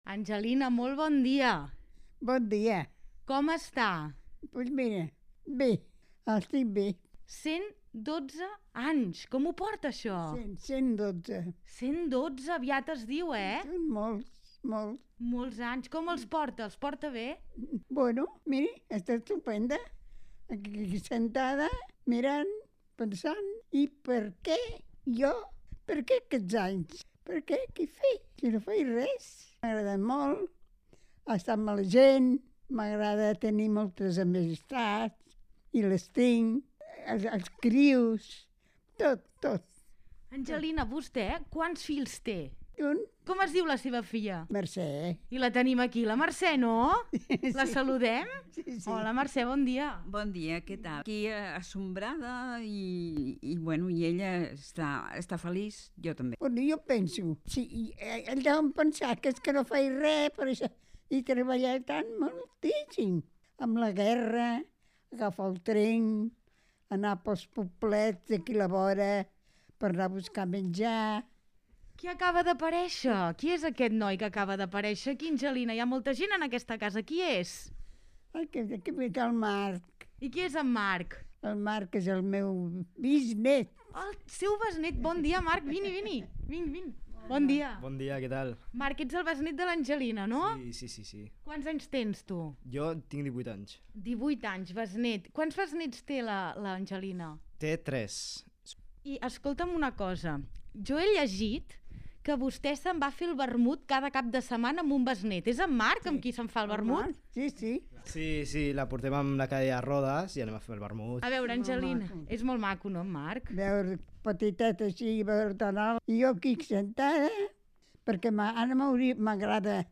En una conversa a El Matí de Ràdio Estel, ens ha obert les portes de casa seva on viuen quatre generacions diferents.